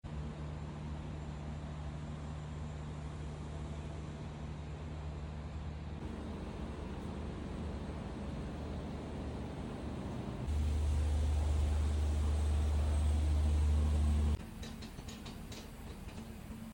1FZ Engine Rebuilding Of Toyota sound effects free download